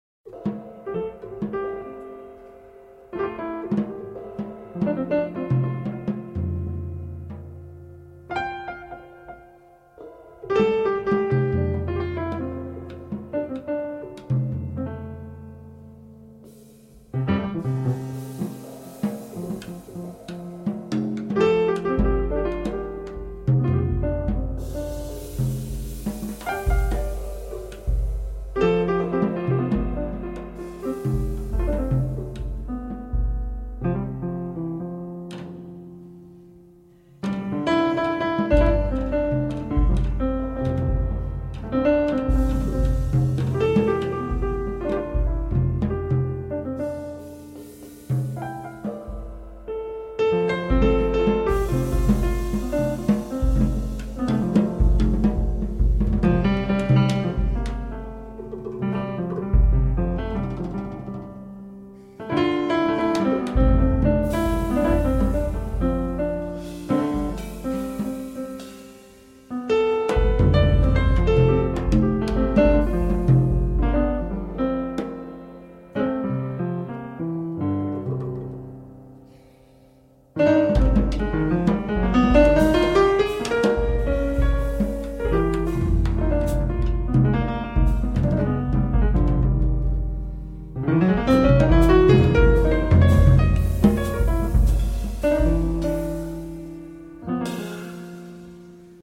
piano
drums